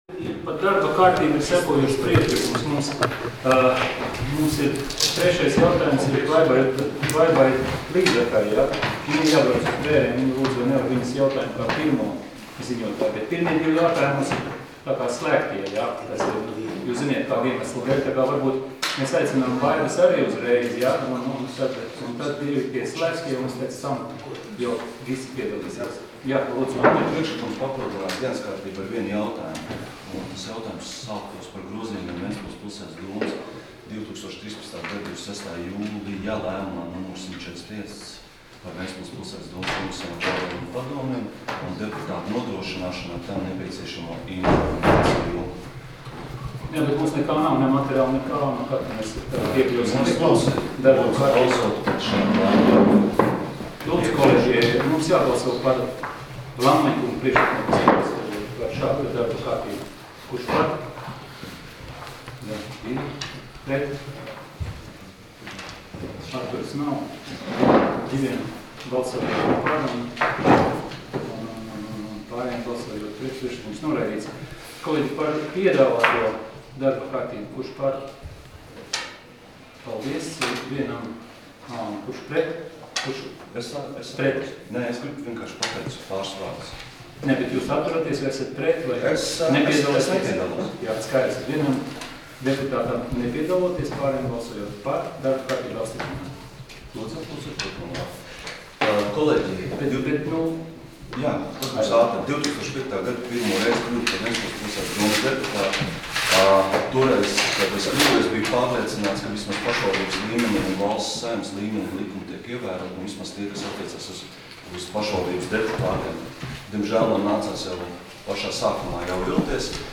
Domes sēdes 11.11.2016. audioieraksts